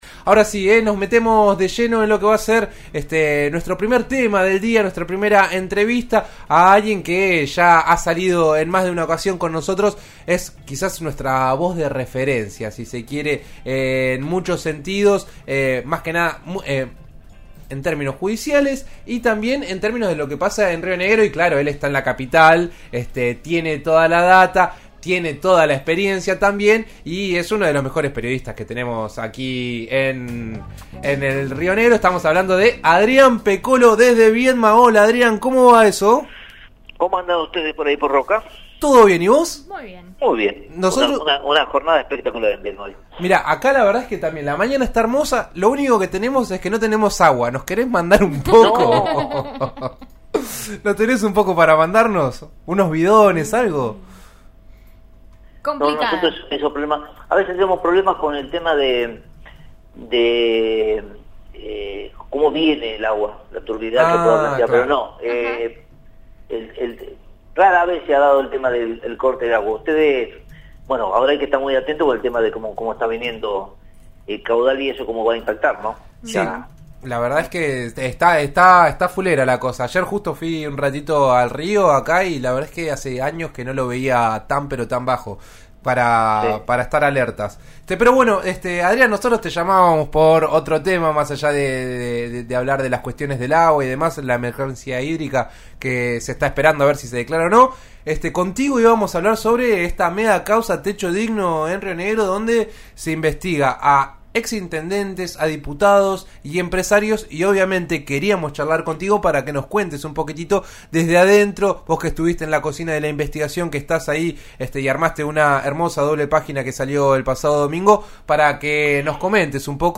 Las investigaciones penales por los planes Techo Digno, iniciadas hace cinco años, comenzarán esta semana con los cargos de imputación para los ex intendentes y los constructores por la irregular utilización de esos fondos nacionales para la construcción de viviendas. En «En eso estamos» de RN Radio habló del tema: